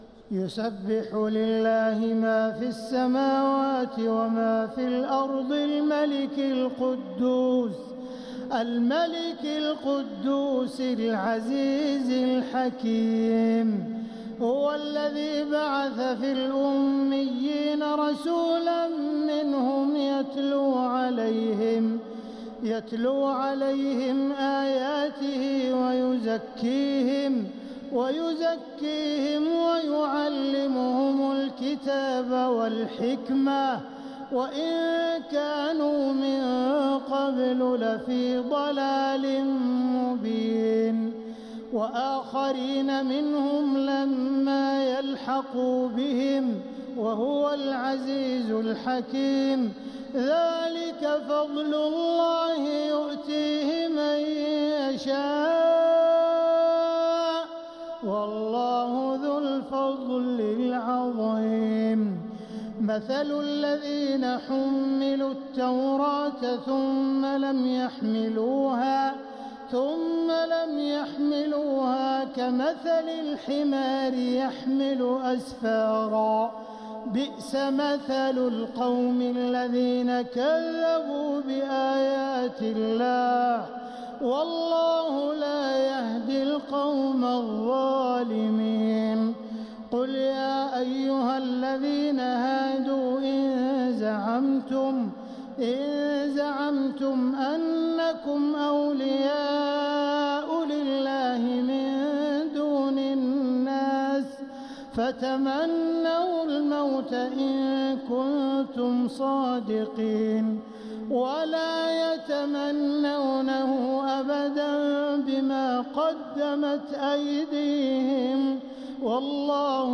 سورة الجمعة | مصحف تراويح الحرم المكي عام 1446هـ > مصحف تراويح الحرم المكي عام 1446هـ > المصحف - تلاوات الحرمين